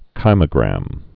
(kīmə-grăm)